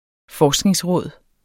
Udtale [ -ˌʁɔˀð ]